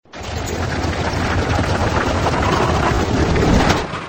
elevatordoor1.mp3